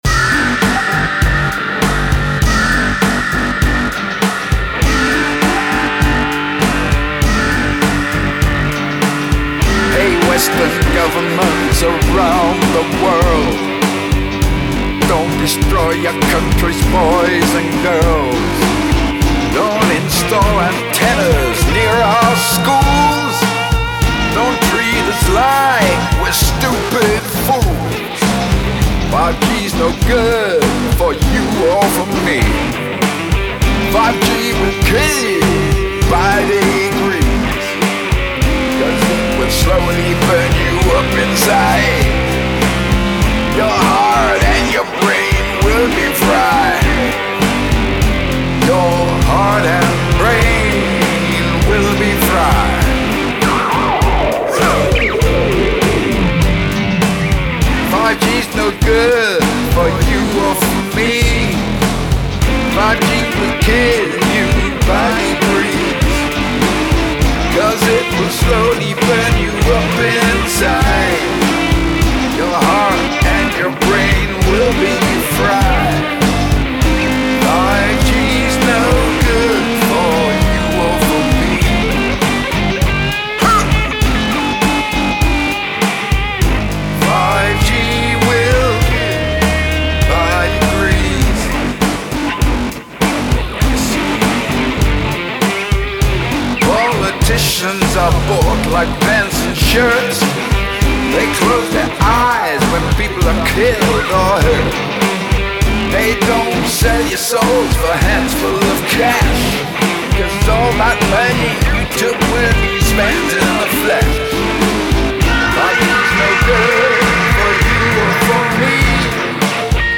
new and classic Rock songs